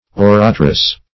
Oratress \Or"a*tress\, n.